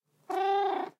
sounds / mob / cat / purreow1.ogg
purreow1.ogg